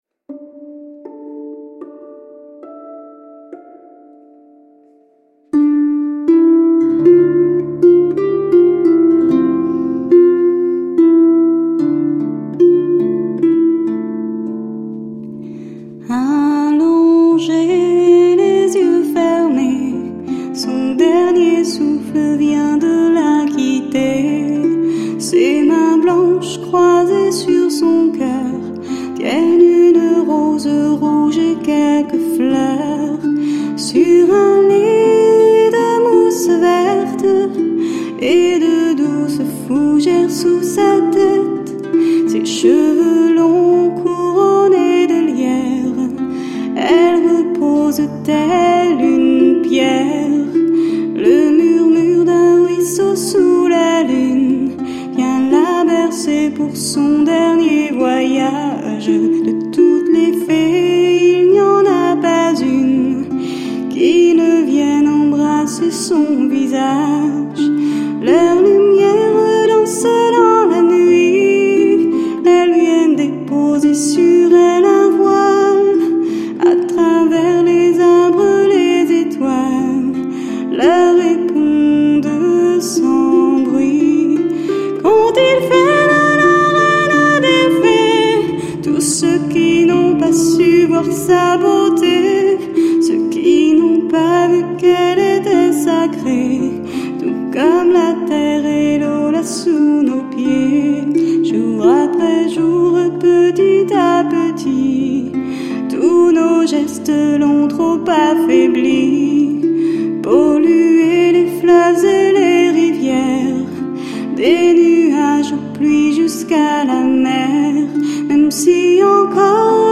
Sa voix se pose avec douceur et naturel sur un répertoire